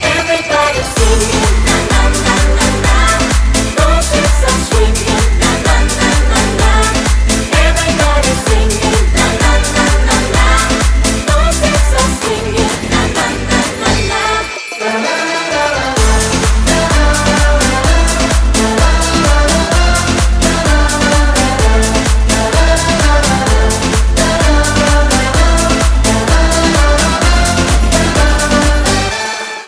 • Качество: 192, Stereo
поп
веселые
dance
карнавальные